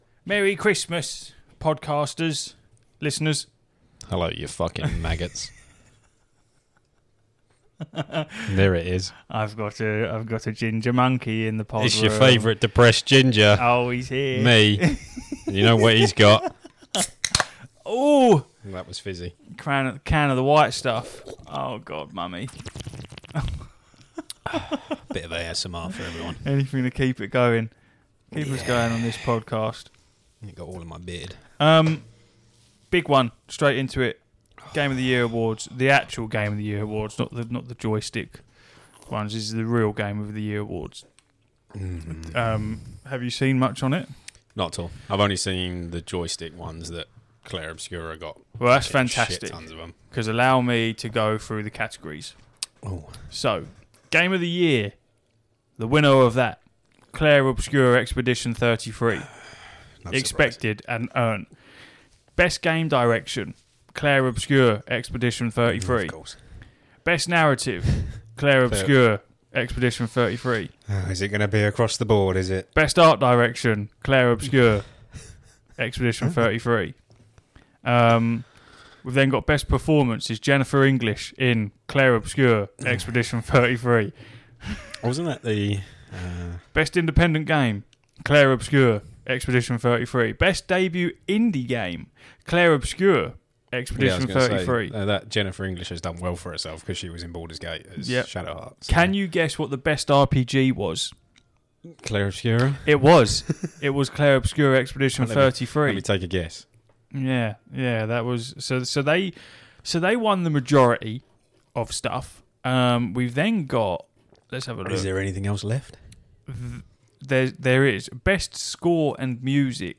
Aiming to provide gaming knowledge and playful banter for the average gamer!